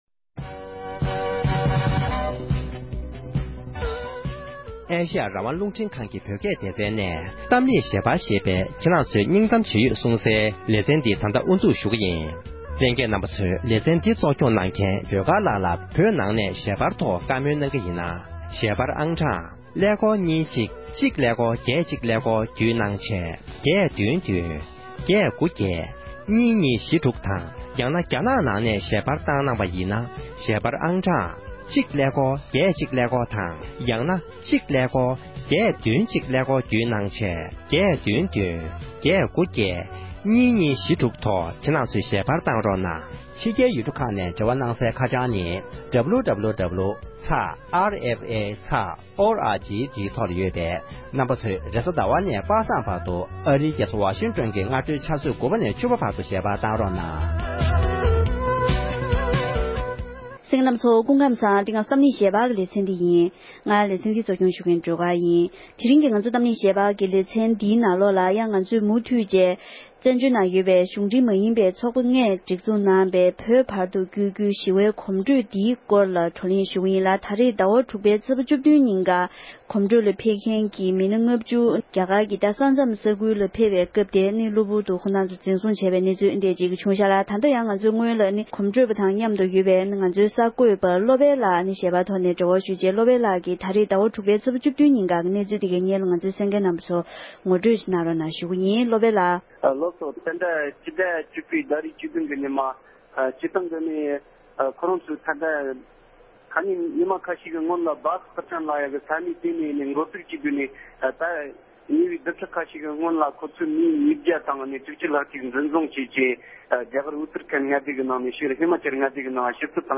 འབྲེལ་ཡོད་མི་སྣར་བགྲོ་གླེང་ཞུས་པ་ཞིག་གསན་རོགས་གནང༌༎